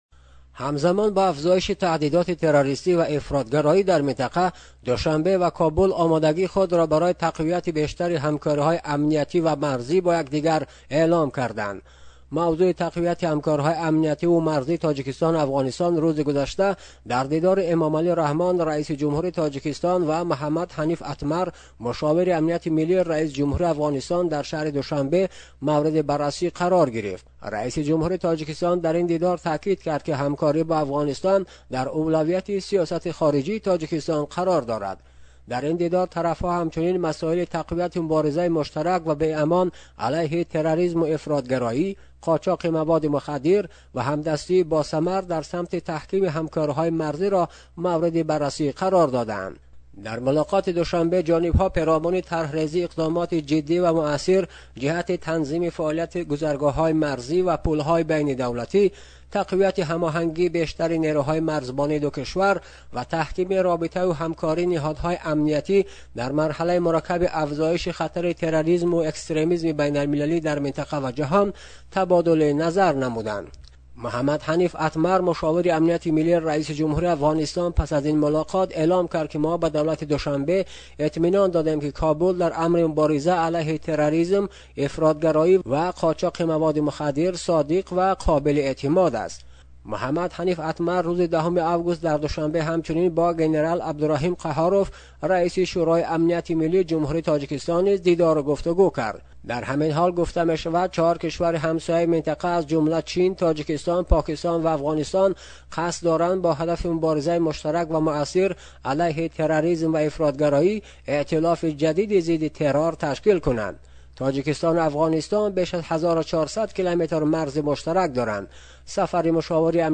аз Душанбе гузориш медиҳад: